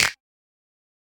Snap (1).wav